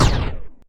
poly_explosion_small2.wav